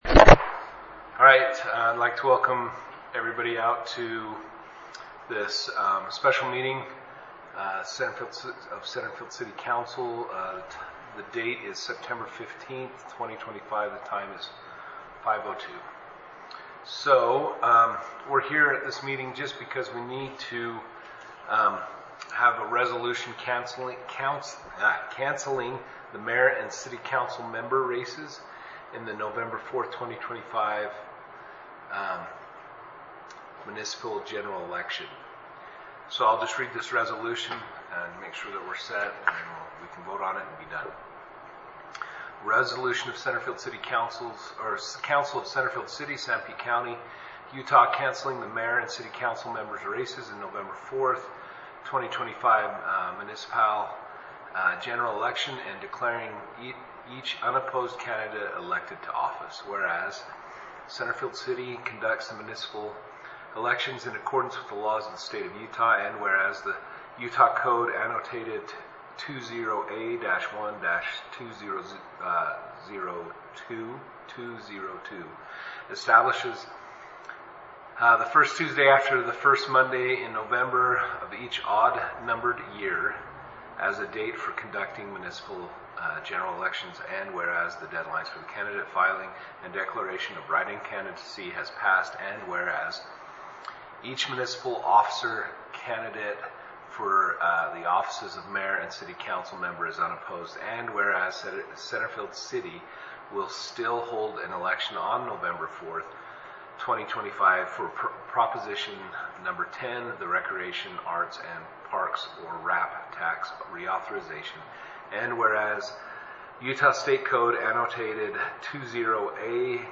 Special City Council Meeting
The meeting will be held in the Centerfield City Hall, 130 South Main, Centerfield; which meeting will begin promptly at 5:00 p.m. The agenda shall be as follows: 1 Roll Call RESOLUTION 2025-6 CANCELLING THE MAYOR AND CITY COUNCIL MEMBER RACES IN THE NOVEMBER 4, 2025, MUNICIPAL GENERAL ELECTION 2 Adjournment NOTE: The agenda items may be discussed in any order.
130 S Main
Centerfield, UT 84622